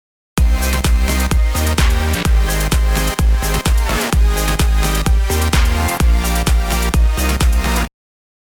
次に、今追加したシンセの素材に、エッジの効いた4つ打ちキックのドラムサンプル「Small Shaker Beat」を重ねます。
2つの素材を重ねただけで、もういい感じのEDMっぽいサウンドができています。